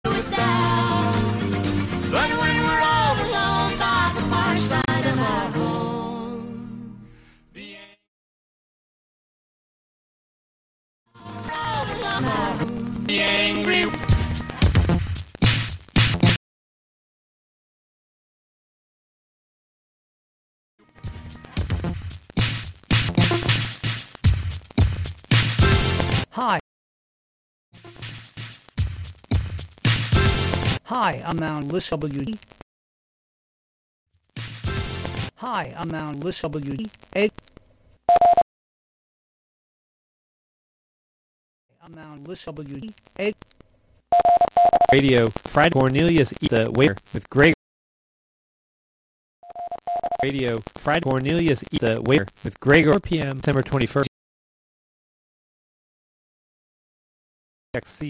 New or artifact, urban or rural – City Folk is a curated field guide for the humble, a study in dirt and bone. Broadcast live from the Hudson studio.